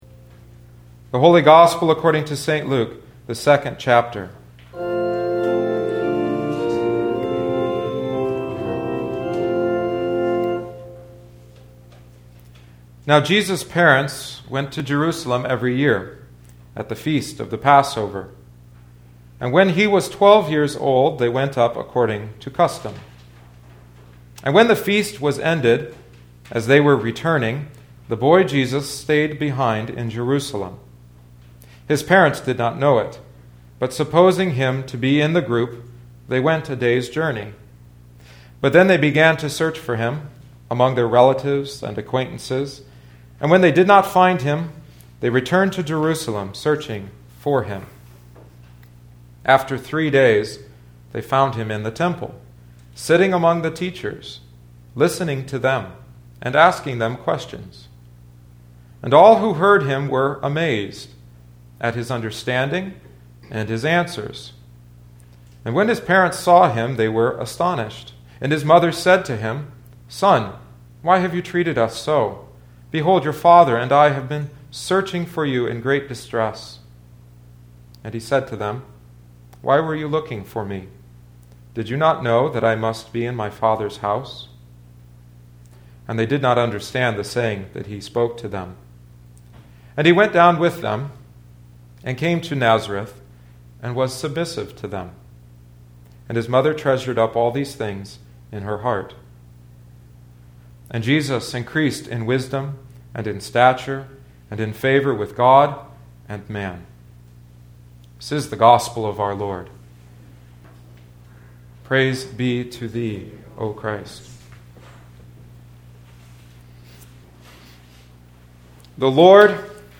Listen to the sermon here: Divine Service 2012-01-07 Email a link to a friend (Opens in new window) Email Share on Facebook (Opens in new window) Facebook Share on X (Opens in new window) X Related